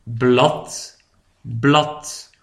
bladeren PRONONCIATION :